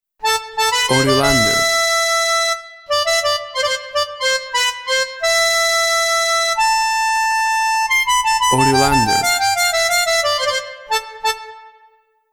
Intimate melody of the bandoneon.
Tempo (BPM) 90